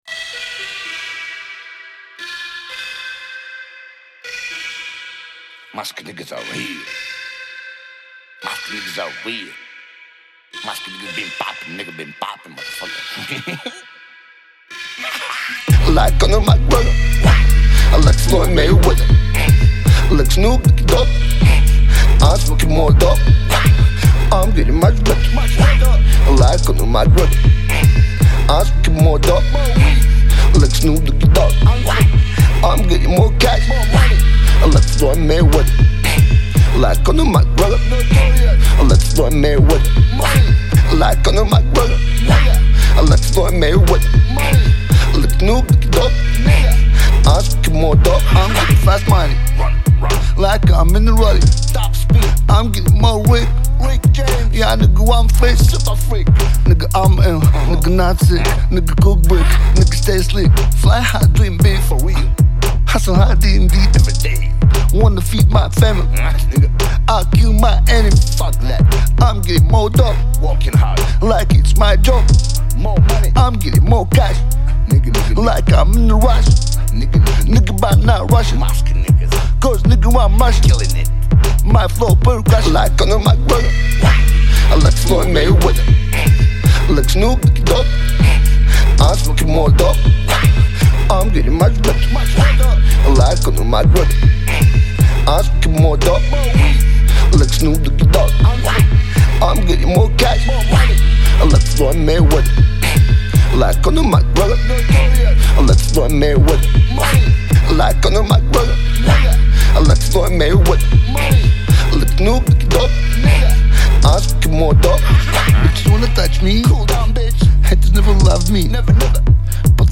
Genre: Rap / hip hop